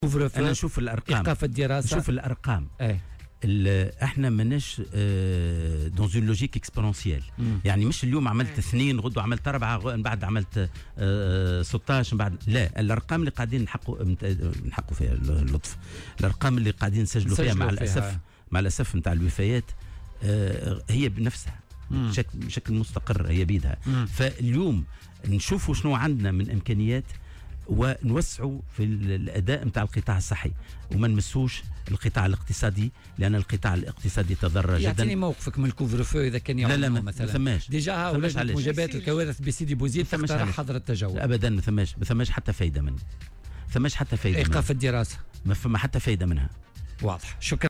وأضاف في مداخلة له اليوم في برنامج "بوليتيكا'" على "الجوهرة أف أم" أنه يجب تطوير آداء المنظومة الصحية، وعدم المساس بالقطاع الاقتصادي الذي تضرّر كثيرا، وفق قوله.